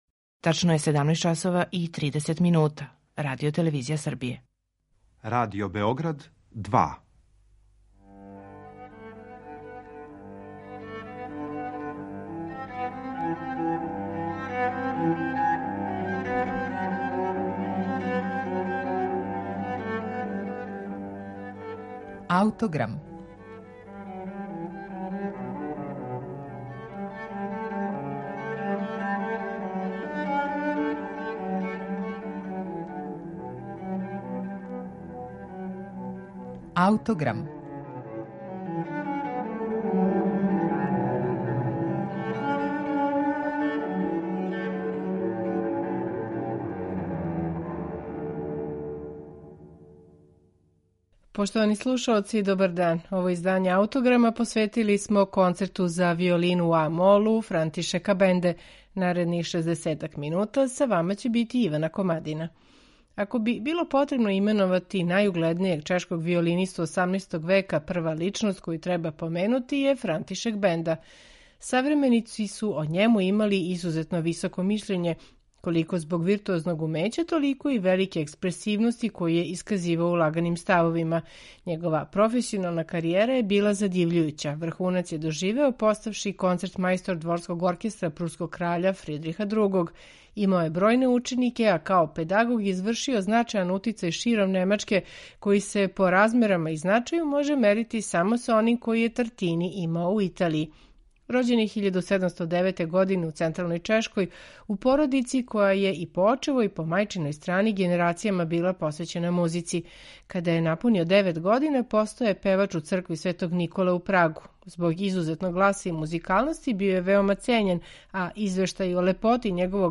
У данашњем Аутограму представићемо Виолински концерт у а-молу Франтишека Бенде